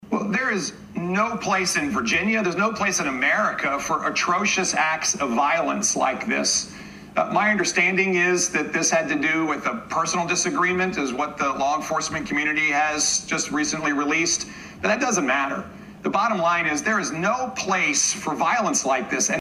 Virginia Governor Glenn Youngkin also appeared on Fox News on Wednesday and spoke about the attack.